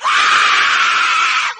falling_skull2.ogg